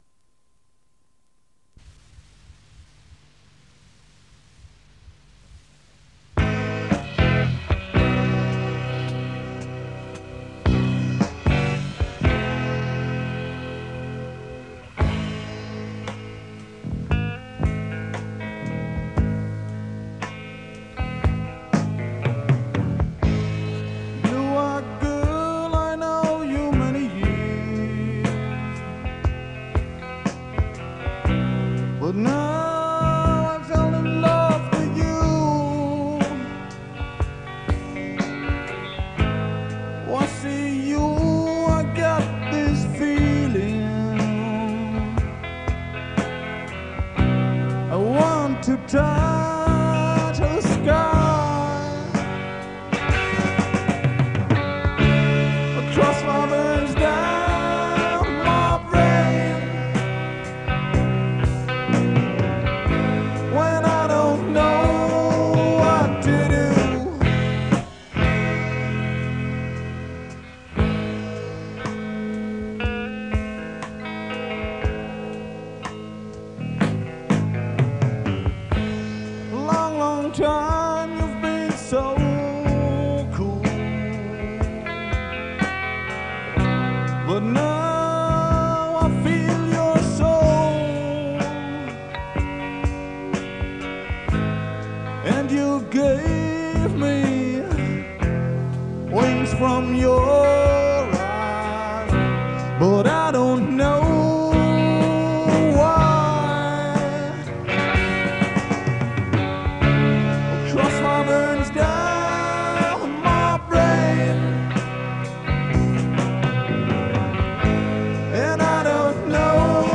Danach wechselte ich nahtlos zur Bremer Rockband „Southbound“, in der ich 4,5 Jahre – bis Mitte 1983 – gedrummt habe.
Studio Aufnahme-unsere „Ballade“..zum Download: ➡rechte Maustaste➡ Ziel speichern unter